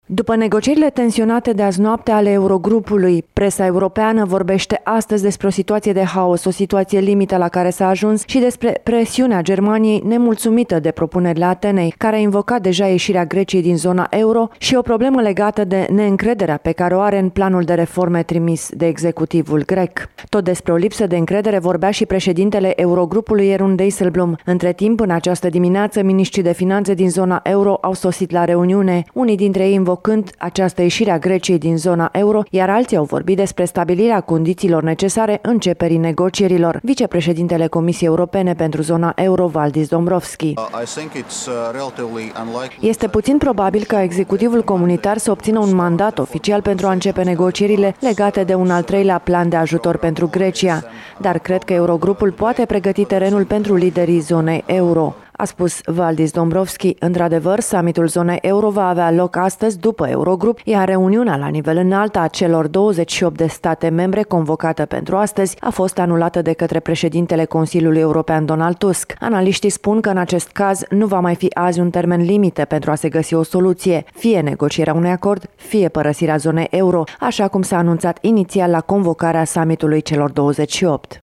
Din Bruxelles relatează